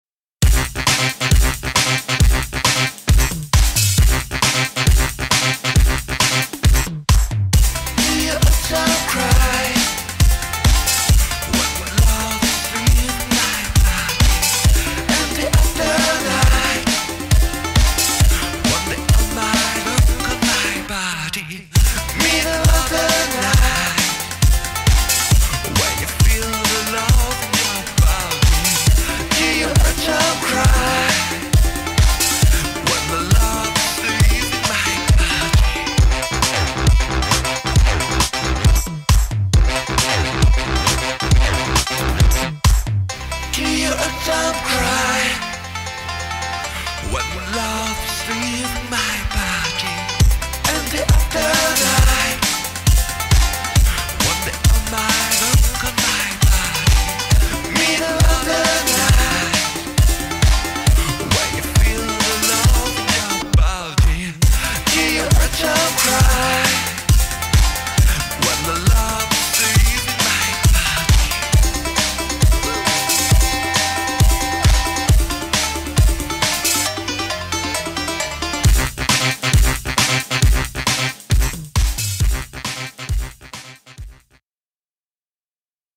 [ ELECTRO / INDIE / OST ]